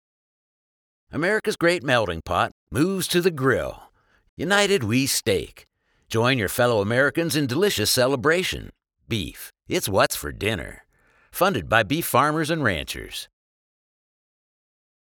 Male
Authoritative, Character, Confident, Cool, Corporate, Deep, Engaging, Friendly, Gravitas, Posh, Reassuring, Smooth, Streetwise, Warm, Versatile, Conversational, Funny, Sarcastic, Assured, Upbeat
A voice like smooth gravel—deep, textured, and riveting.
Audio equipment: Pro, acoustically treated studio with Source-Connect, Grace m101 preamp, Audient iD4 interface